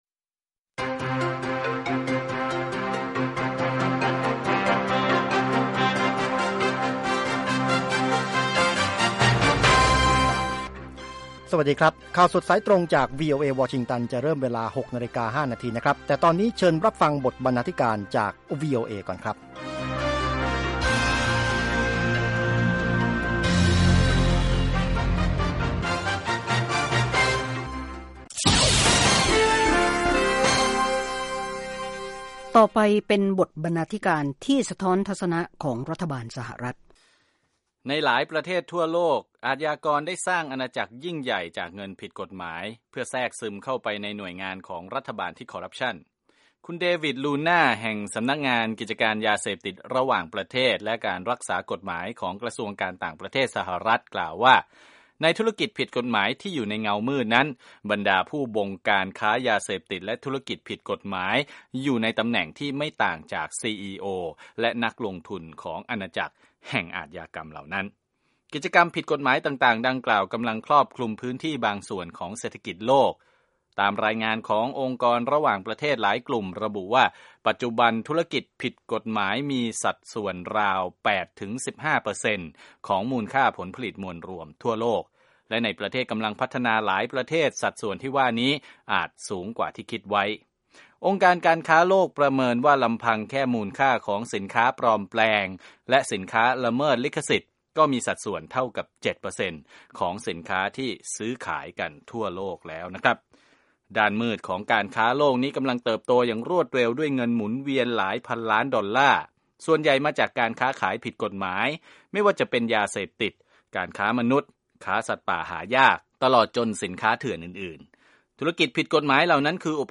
ข่าวสดสายตรงจากวีโอเอ ภาคภาษาไทย 6:00 – 6:30 น. สำหรับวันศุกร์ที่ 16 ตุลาคม 2558